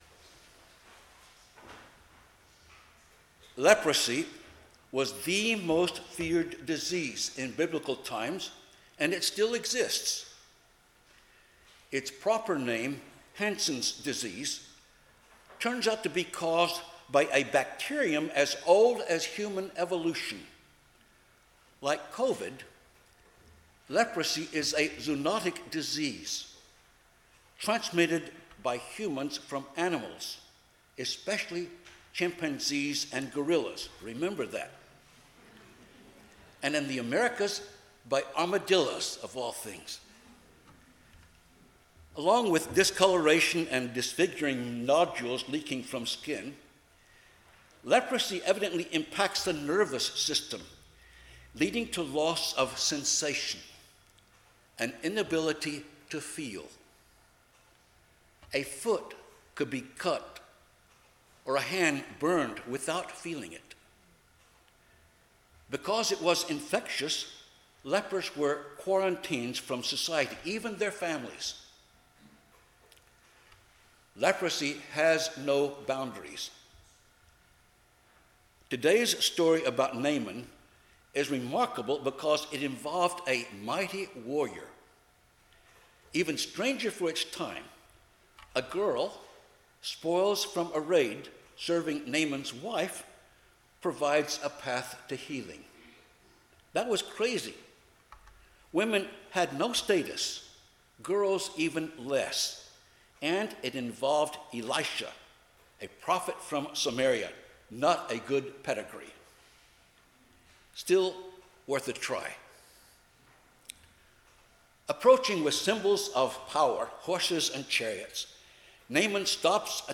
Passage: 2 Kings 5:1–3,7–15c, Psalm 111, 2 Timothy 2:8–15, Luke 17:11–19 Service Type: 10:00 am Service